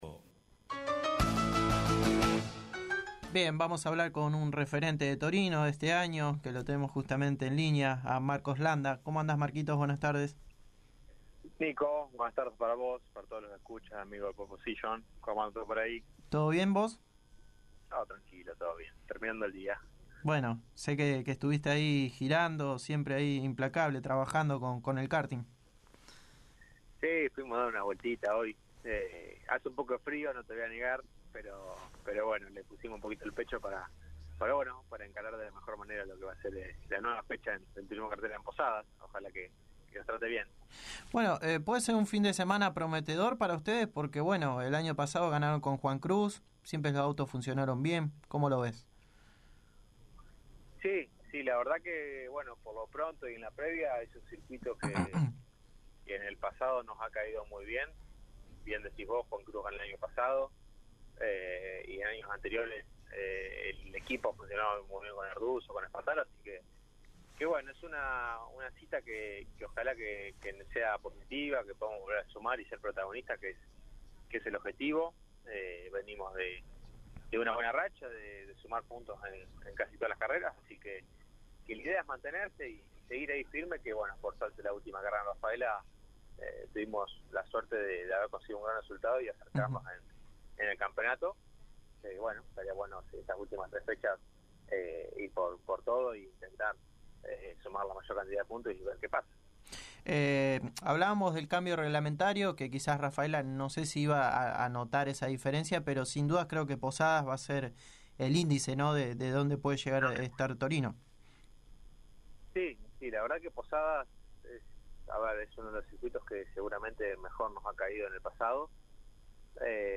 El piloto uruguayo pasó por los micrófonos de Pole Position y habló de como se preparan para una nueva fecha en Posadas.